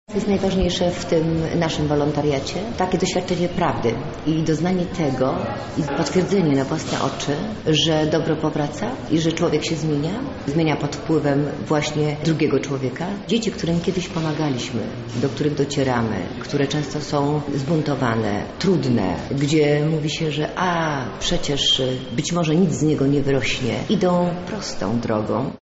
Pod takim hasłem odbyła się dziś konferencja z okazji 10-lecia Akademickiego Punktu Wolontariatu.